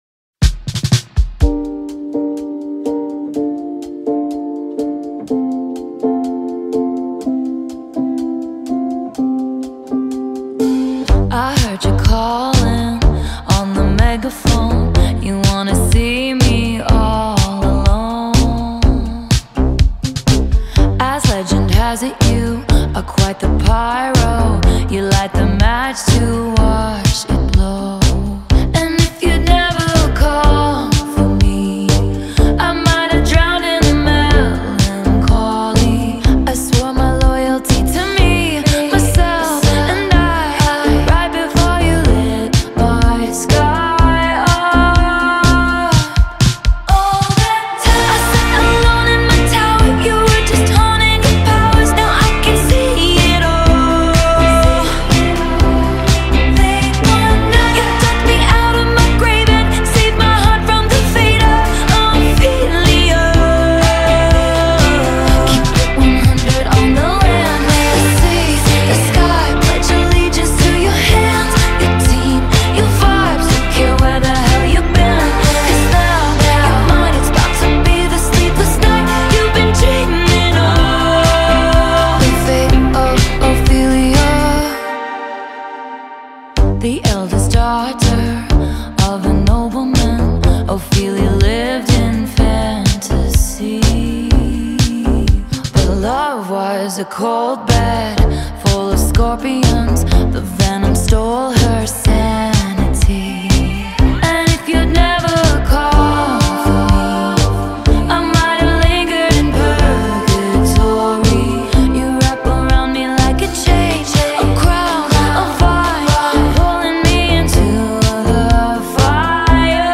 a more luminous, vibrant, and energetic pop sound
A luminous pop moment